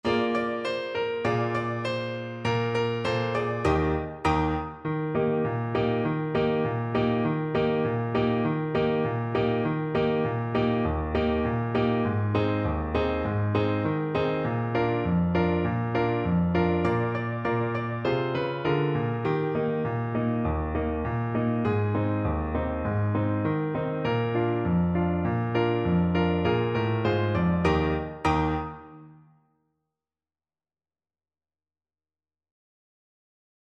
Clarinet
Raucous, two in a bar =c.100
2/2 (View more 2/2 Music)
Eb major (Sounding Pitch) F major (Clarinet in Bb) (View more Eb major Music for Clarinet )
Traditional (View more Traditional Clarinet Music)
knees_up_mother_CL_kar1.mp3